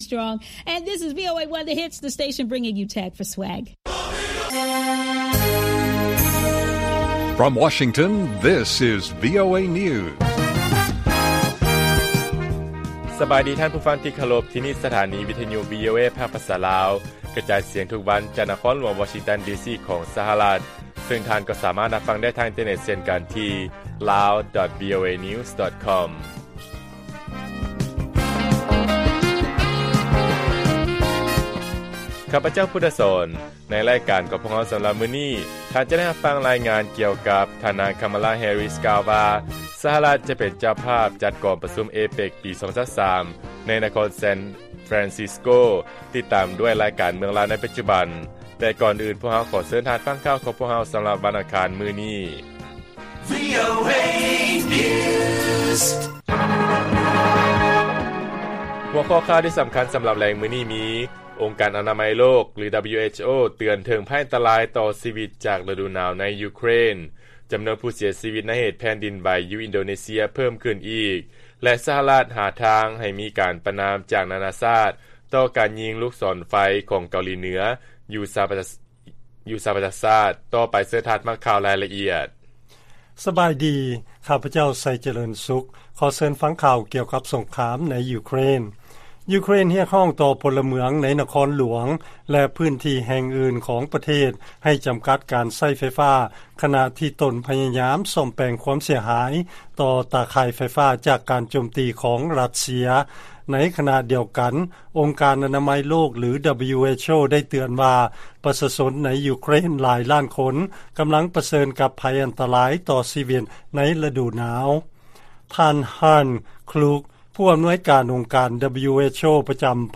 ລາຍການກະຈາຍສຽງຂອງວີໂອເອ ລາວ: ອົງການອະນາໄມໂລກ WHO ເຕືອນເຖິງ 'ໄພອັນຕະລາຍຕໍ່ຊີວິດ' ຈາກລະດູໜາວ ຢູ່ໃນຢູເຄຣນ